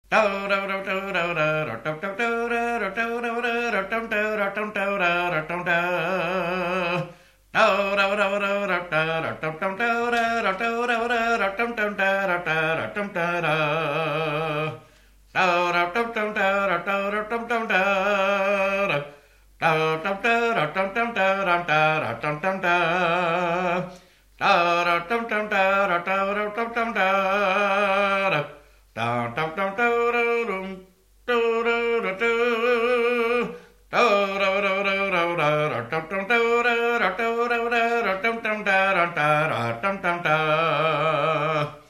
Fanfare
strophique
Pièce musicale inédite